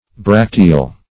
Bracteal \Brac"te*al\